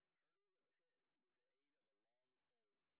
sp09_street_snr20.wav